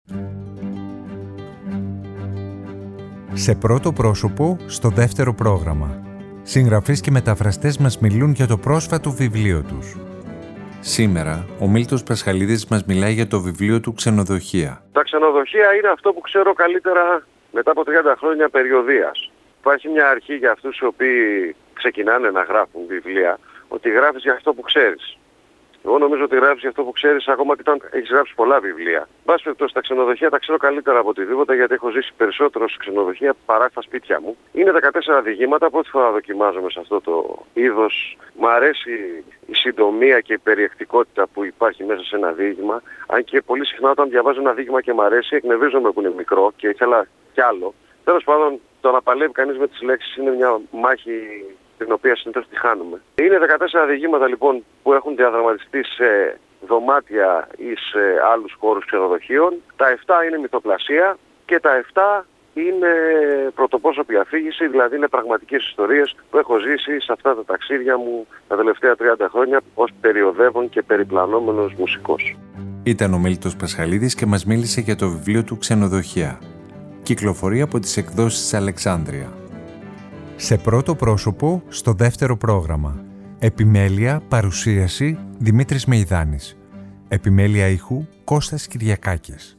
Σήμερα ο Μίλτος Πασχαλίδης μας μιλάει για το βιβλίο του «Ξενοδοχεία».